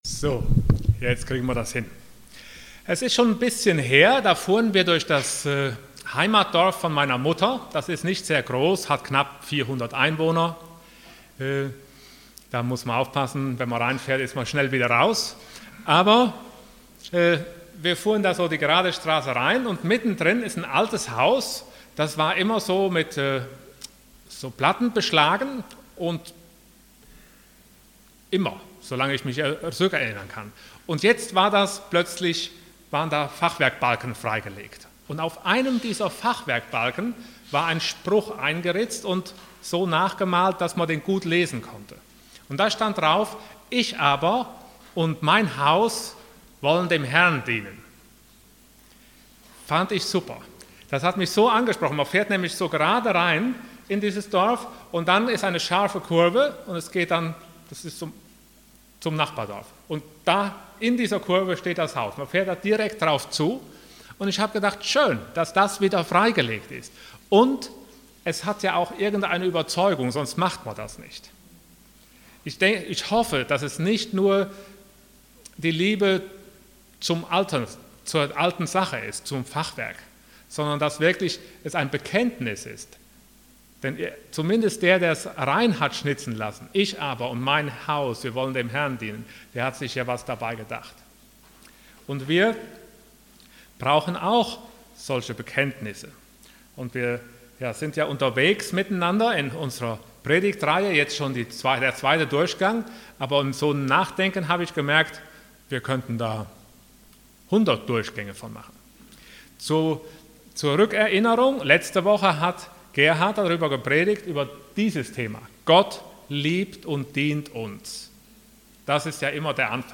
Passage: Mark 12:30 Dienstart: Sonntag Morgen